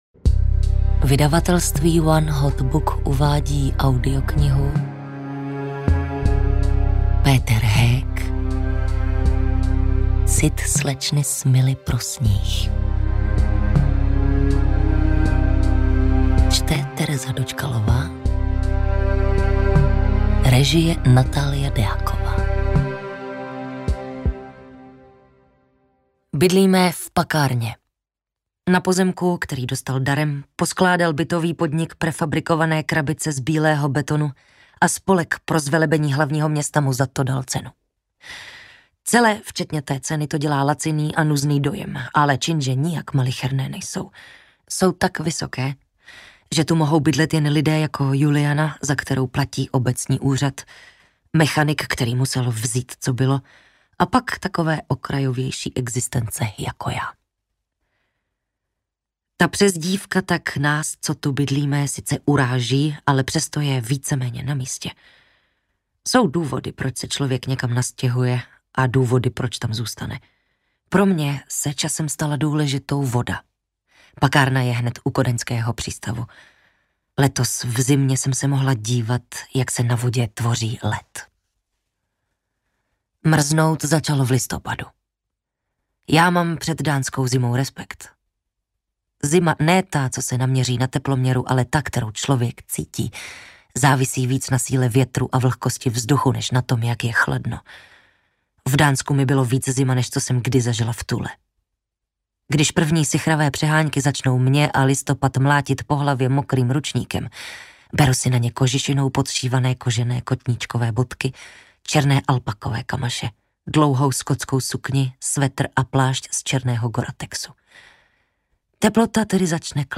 Cit slečny Smilly pro sníh audiokniha
Ukázka z knihy
• InterpretTereza Dočkalová